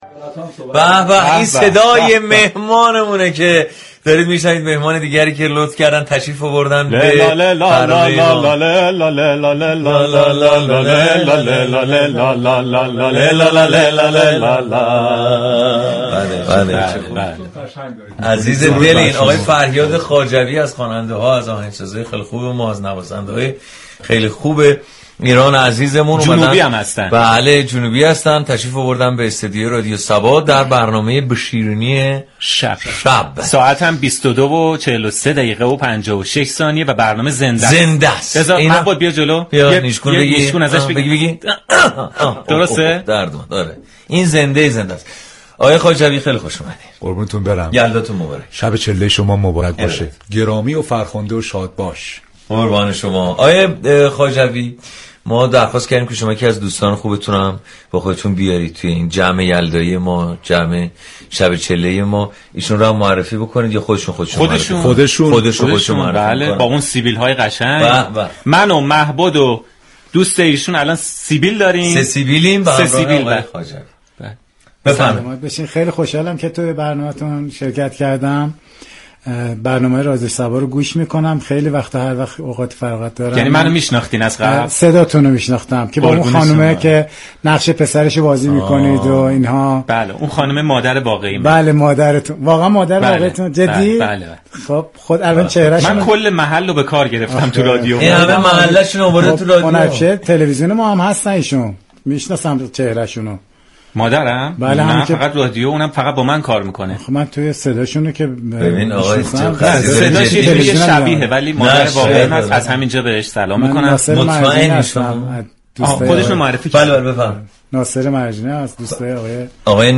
دراین برنامه خواننده جنوبی كشورمان با اجرای زنده موسیقی و ترانه های شاد و گفتگو درباره موسیقی های محلی جنوبی لحظات به یاد ماندنی را برای شنوندگان ساخت.
در ادامه شنونده این بخش از گفتگو باشید.